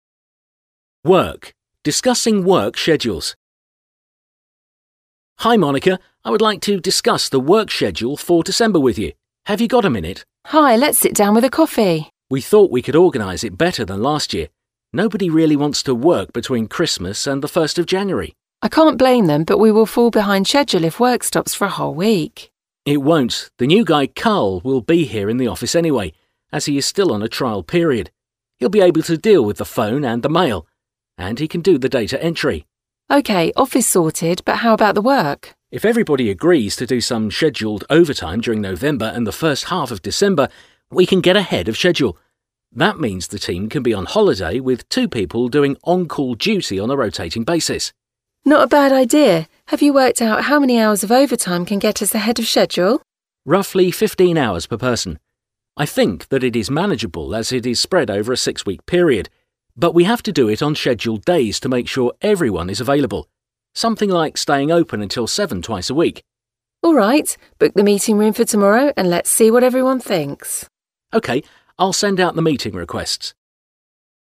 Hasznos munkahelyi párbeszédek: Időbeosztás megvitatása